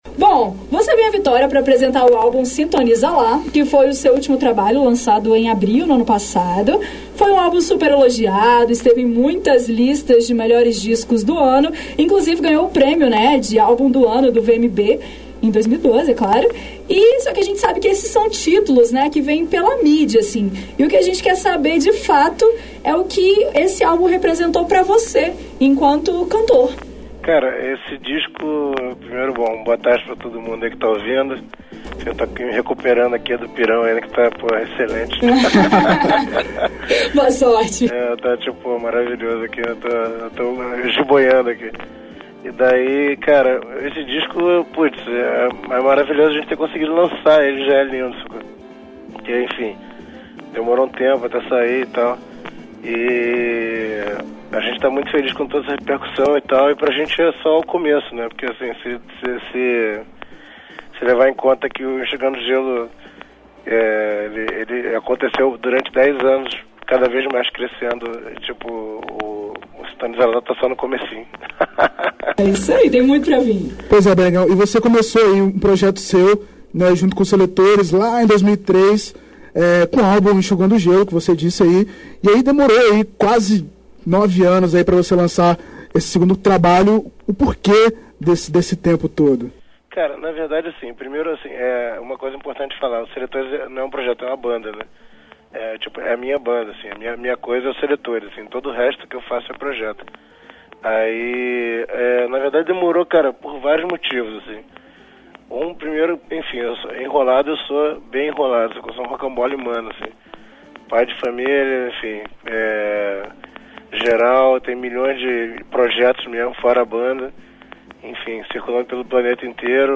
O cantor BNegão concedeu entrevista ao Programa Tardes Infinitas nesta sexta-feira (05) e sua participação foi reprisada no Revista Universitária.
entrevista_b_negao_96.mp3